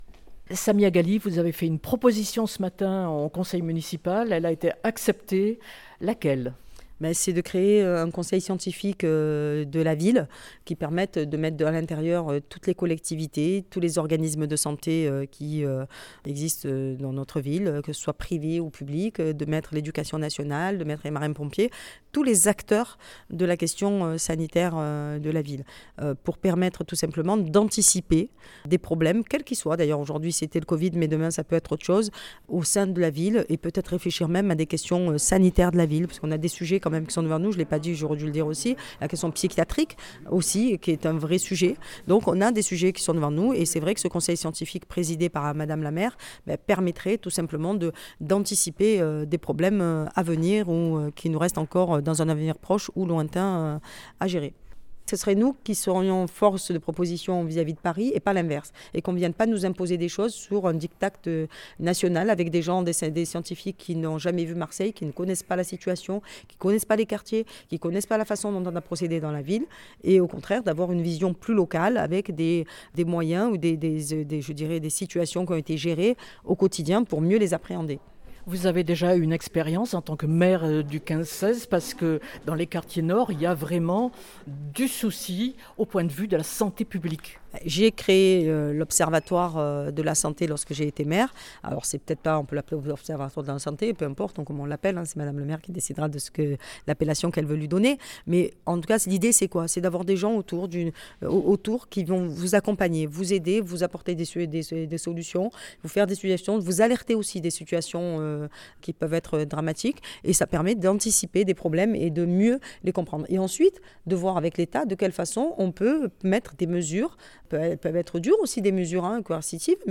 son_copie_petit-440.jpgSamia Ghali, deuxième adjointe propose pour sa part la création d’un conseil scientifique local: «Il permettra de répondre efficacement aux urgences sanitaires locales, qu’il s’agisse de la pandémie de Covid-19 que nous traversons, ou d’autres questions sanitaires». Entretien.